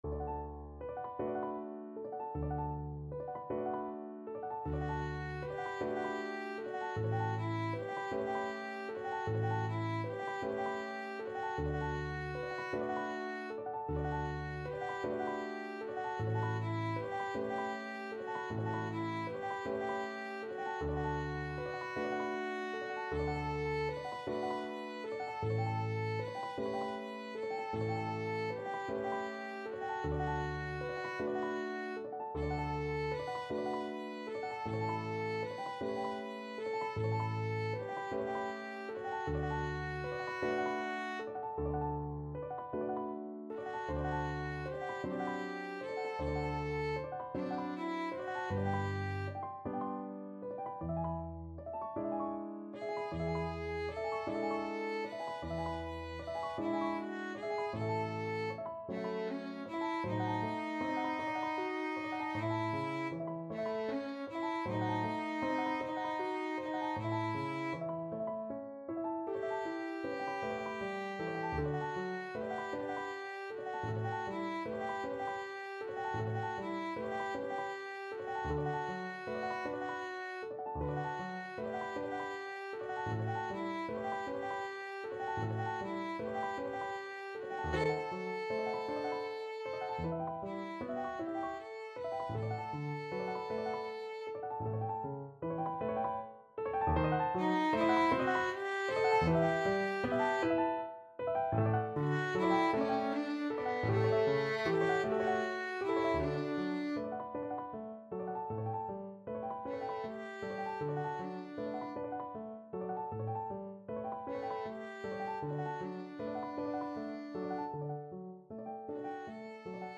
Violin 1Violin 2Piano
6/8 (View more 6/8 Music)
Moderato . = c. 52
Classical (View more Classical Violin Duet Music)